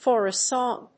アクセントfor a sóng